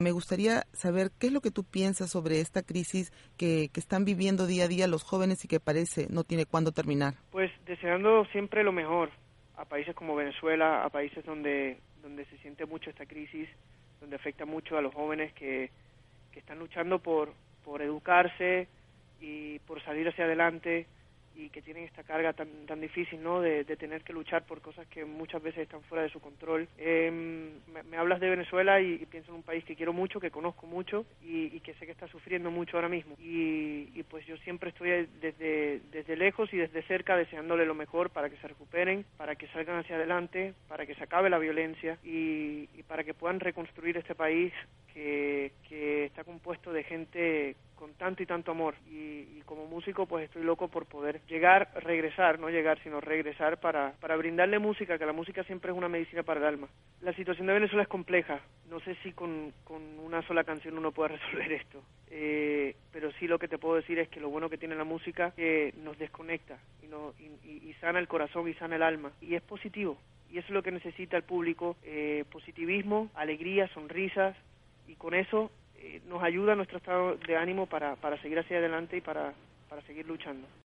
El cantautor puertorriqueño en entrevista con la Voz de América opina sobre las crisis que se vive en ambos países y considera, que aunque una canción no puede solucionar los problemas sociales, sí ayuda porque sana el alma y el corazón.